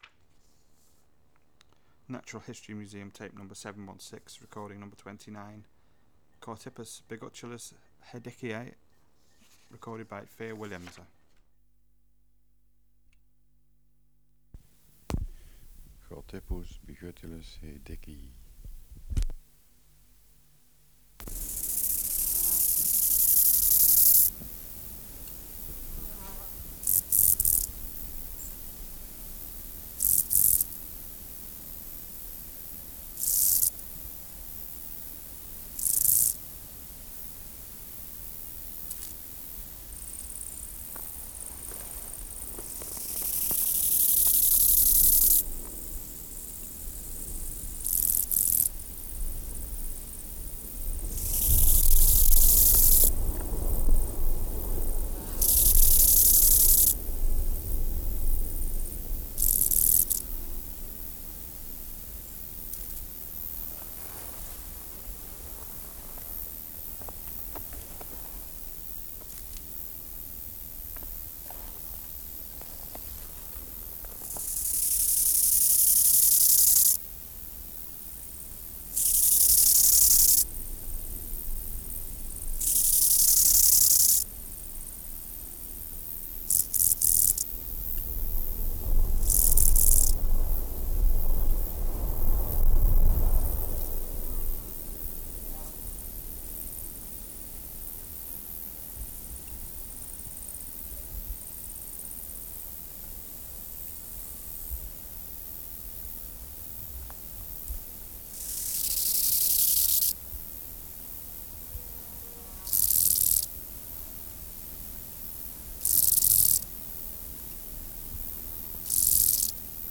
573:29 Chorthippus biguttulus hedickei (716r29) | BioAcoustica
Project: Natural History Museum Sound Archive
Air Movement: Windy
Extraneous Noise: Flies, thunder wind and other Orthoptera
Isolated male
Microphone & Power Supply: Sennheiser K30AV with ME88 head (filter at III) Distance from Subject (cm): 8